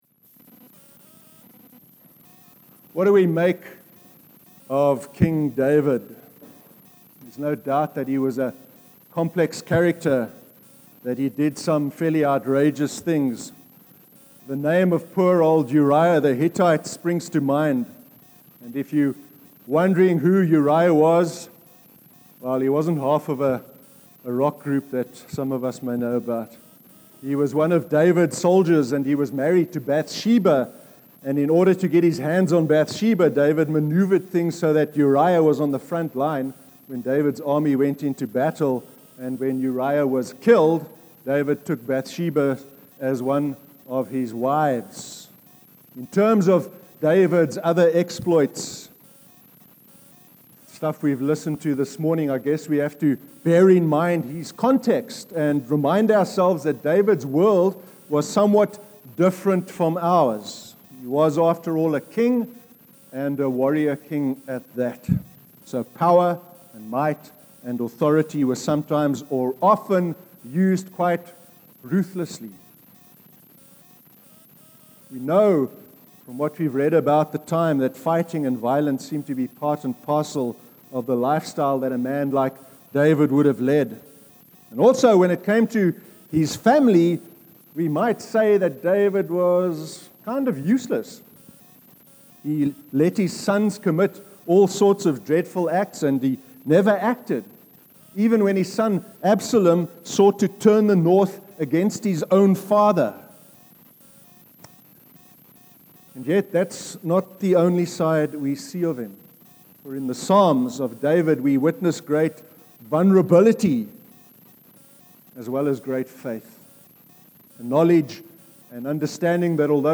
07/02/2016 sermon – God’s promise to David (2 Samuel 7)